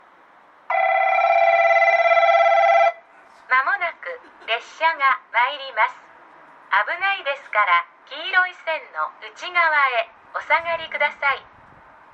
この駅では接近放送が設置されています。
接近放送普通　博多行き接近放送です。